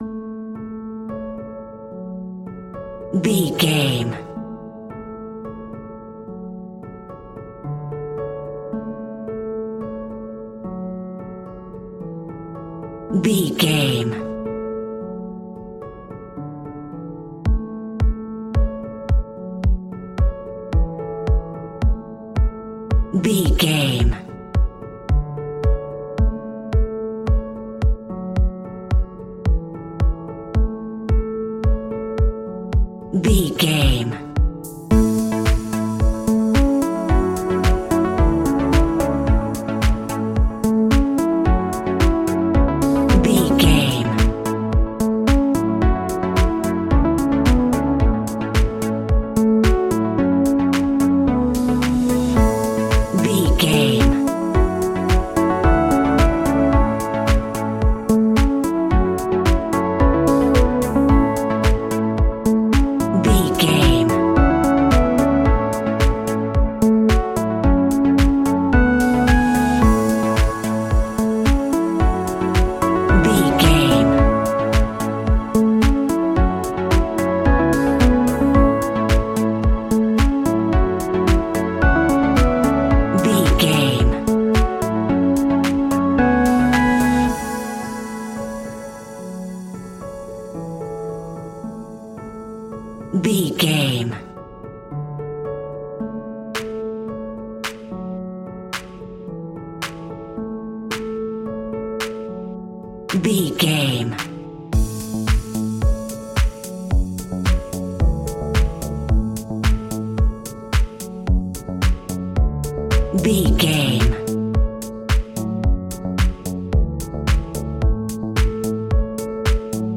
Ionian/Major
groovy
uplifting
driving
energetic
synthesiser
drums
electric piano
electronic
techno